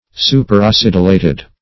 Superacidulated \Su`per*a*cid"u*la`ted\, a. Acidulated to excess.
superacidulated.mp3